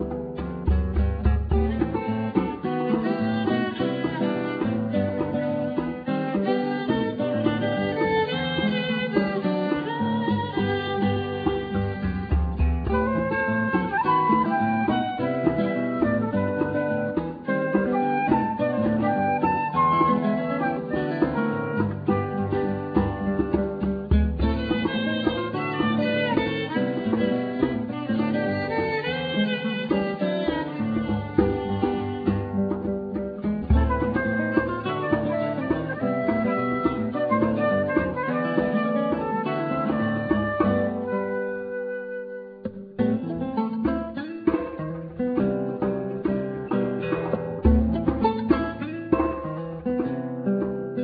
Guitar
Flute
Viola,Violin
Drums,Vibrapone,Cembalo,Piano